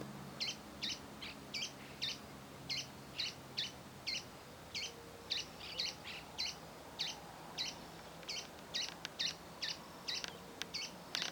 Pijuí Frente Gris (Synallaxis frontalis)
Nombre en inglés: Sooty-fronted Spinetail
Localidad o área protegida: La Rancherita
Condición: Silvestre
Certeza: Vocalización Grabada